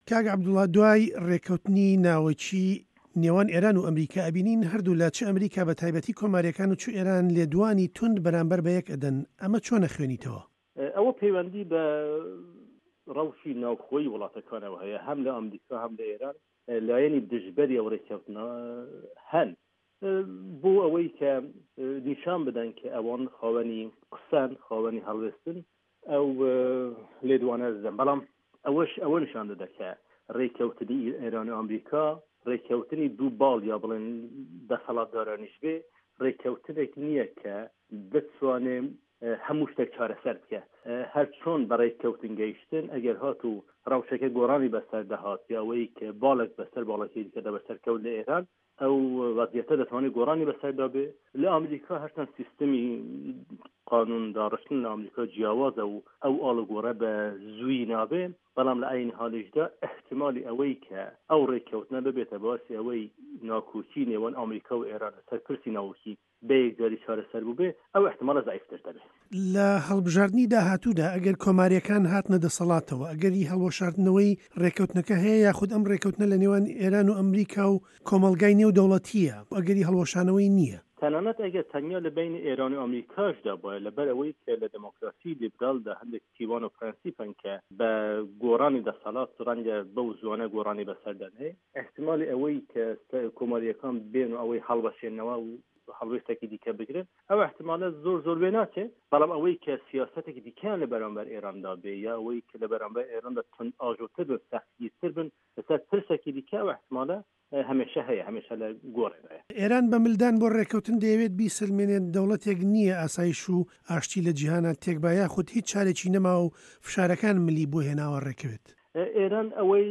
ڕۆژهه‌ڵاتی ناوه‌ڕاست - گفتوگۆکان